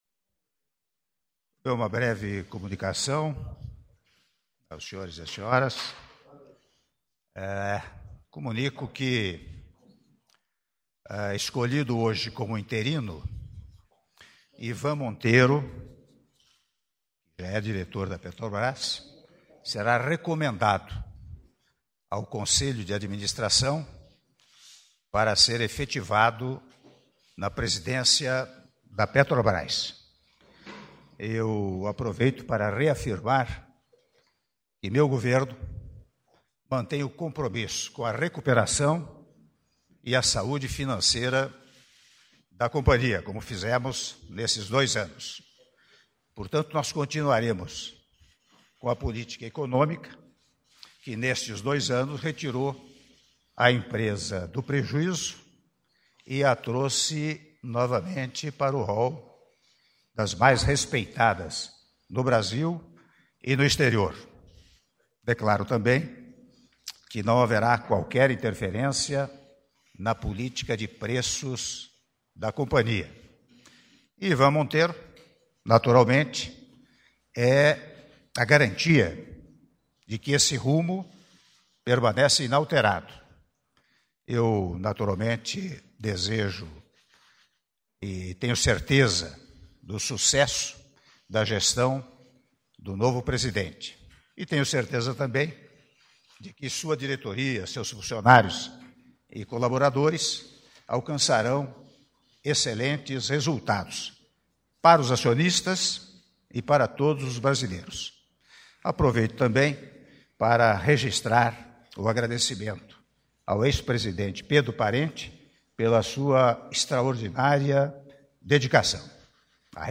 Áudio da declaração à imprensa do Presidente da República, Michel Temer - Palácio do Planalto (02min17s)